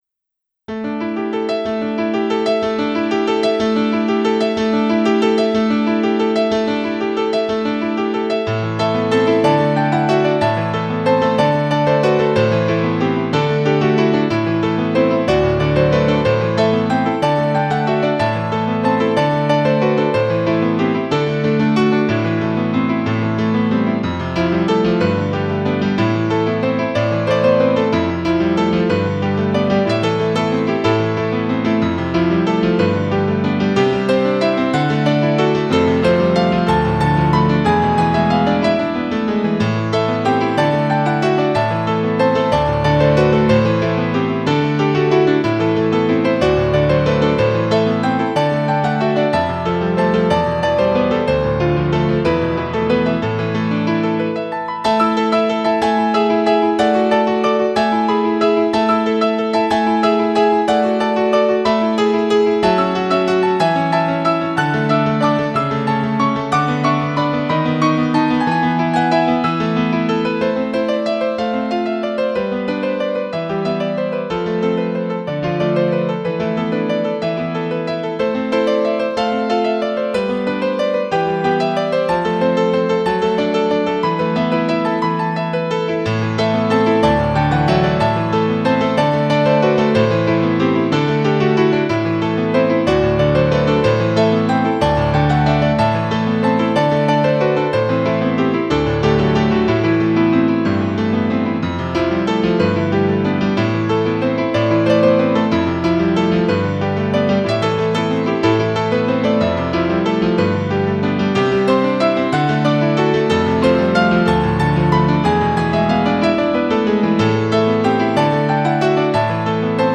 Música para piano
miércoles, junio 02, 2010 Música para piano ... pues eso: música para piano .